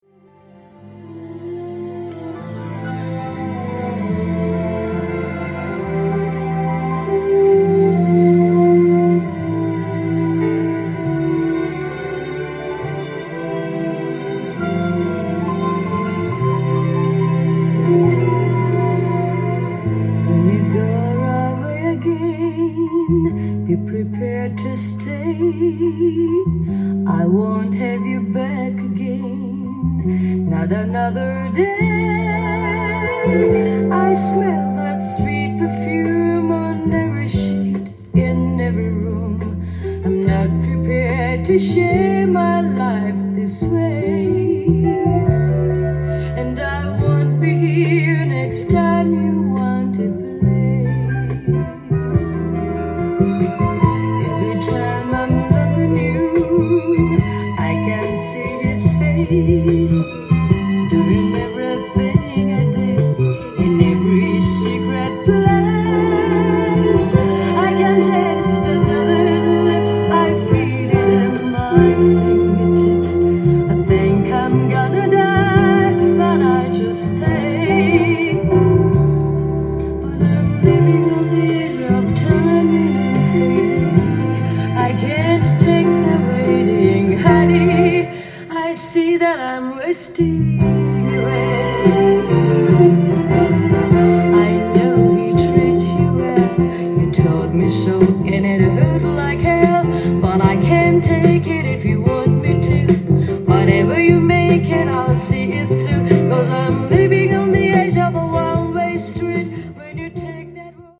ジャンル：JAZZ-VOCAL
店頭で録音した音源の為、多少の外部音や音質の悪さはございますが、サンプルとしてご視聴ください。